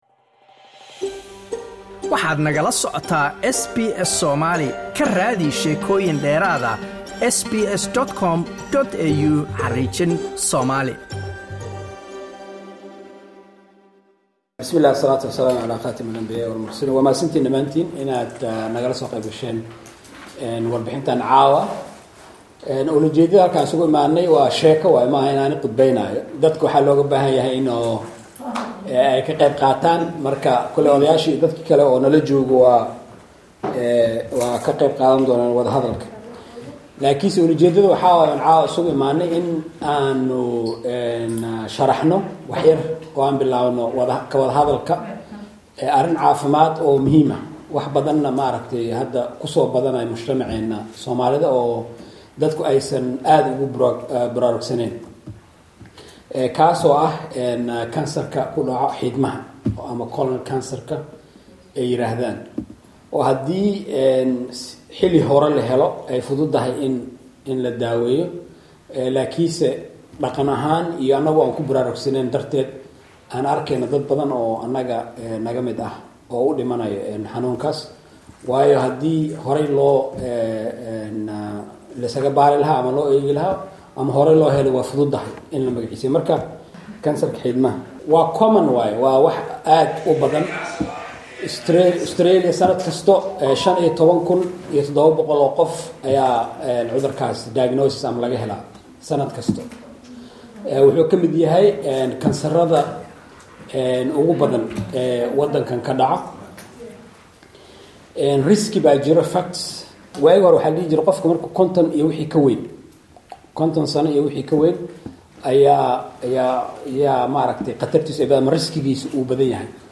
Kulan bulshada looga wacyi-galinayey halista uu leeyahay Kansarka Mindhicirada (Bowel Cancer) ku dhaca ayaa xalay fiidkii (26 August 2024) ka dhacay xaafada Heidelberg West.
Laanta Afsoomaaliga ee Idaacada SBS oo kulanka ka qaybgashay ayaa idiin diyaarisay kalimadii wacyi-galinta ee ku aadanayd Kansarka Xiidmaha.